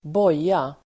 Ladda ner uttalet
Uttal: [²båj:a]